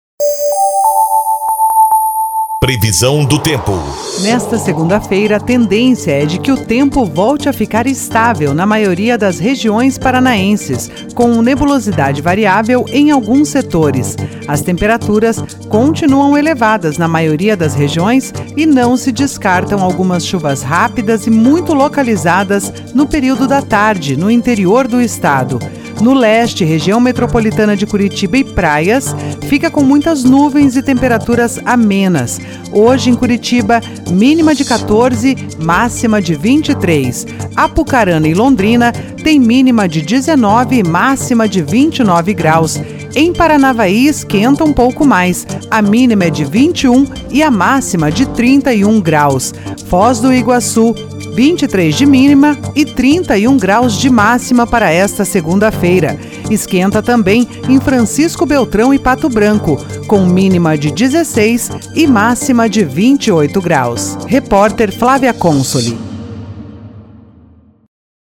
PREVISÃO DO TEMPO 20/11/23